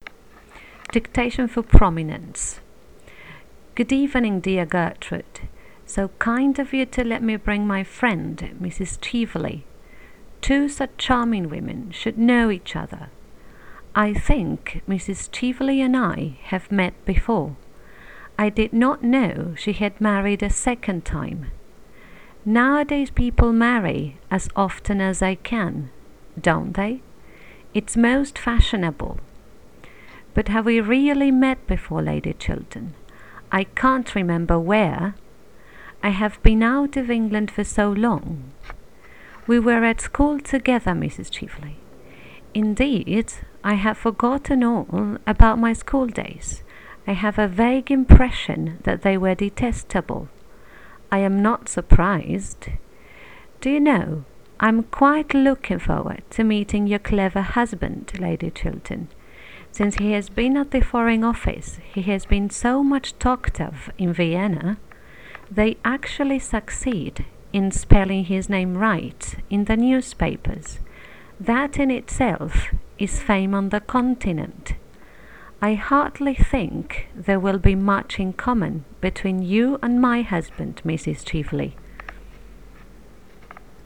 An ideal Husband Dictation for prominence only.wav